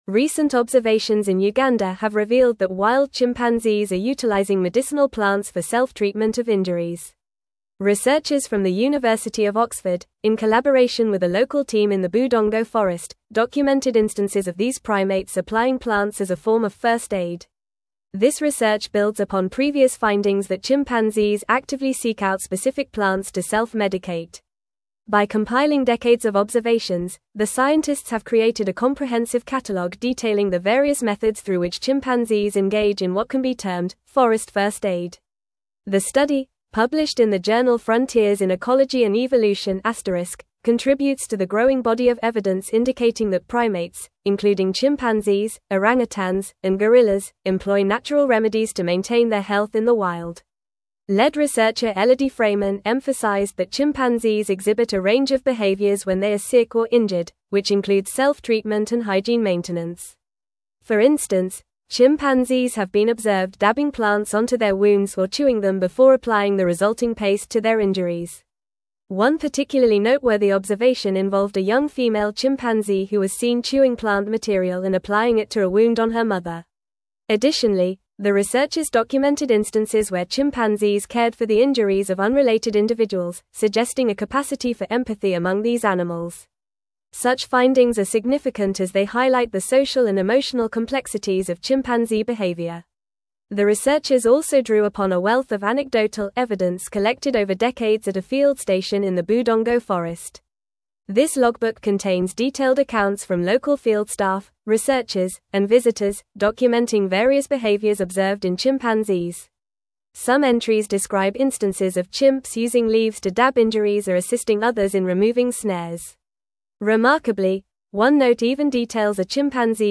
Fast
English-Newsroom-Advanced-FAST-Reading-Chimpanzees-Use-Medicinal-Plants-for-Self-Care-in-Uganda.mp3